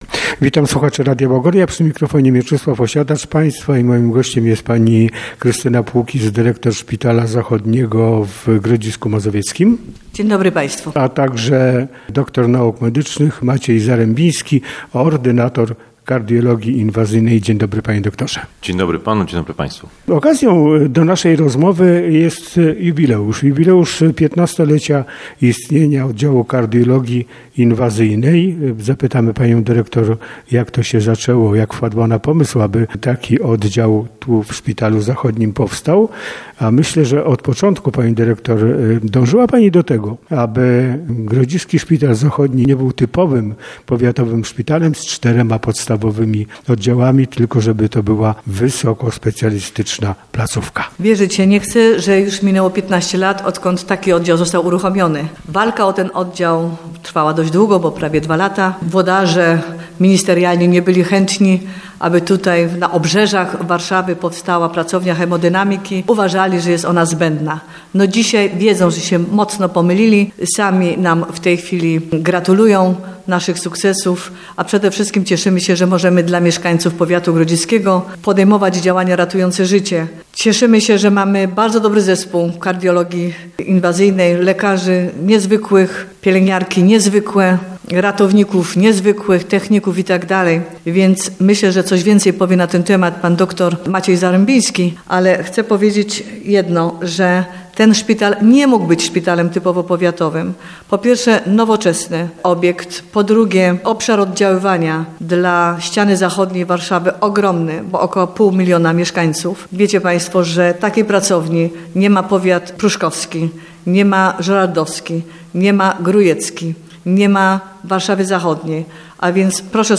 Wywiad w 15 lecie Oddziału Kardiologii Inwazyjnej - Szpital Zachodni w Grodzisku Mazowieckim